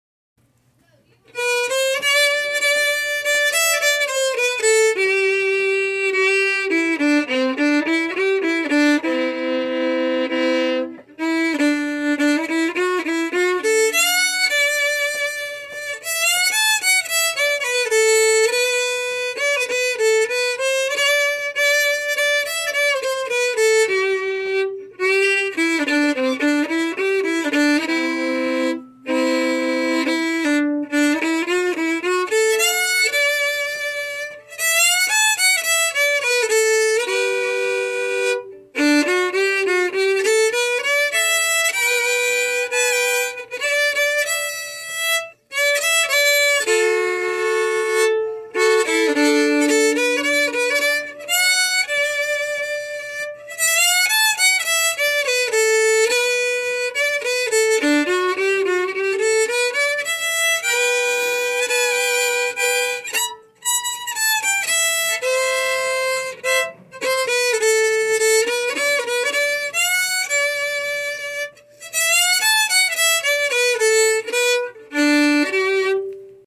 Key: G
Form: Reel (Stomp? Bluegrass)
Played slowly for learning